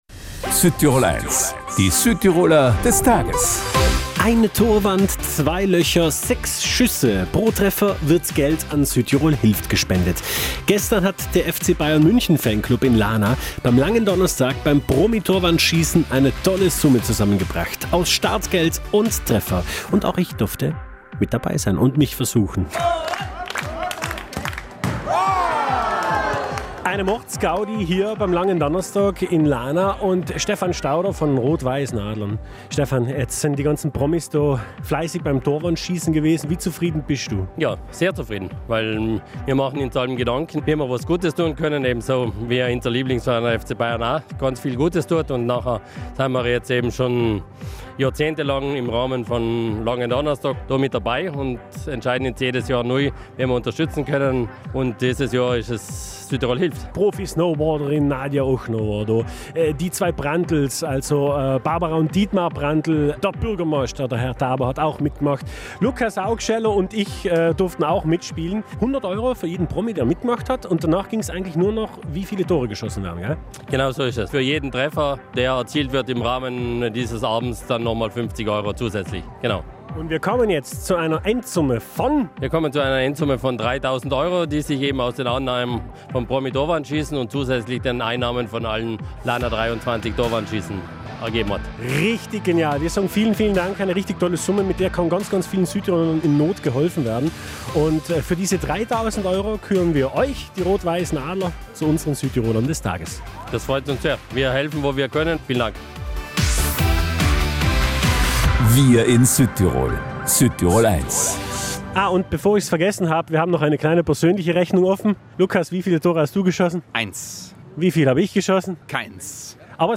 Angespannte Stimmung, Nervosität und Zielgenauigkeit für den guten Zweck.